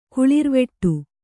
♪ kuḷirveṭṭu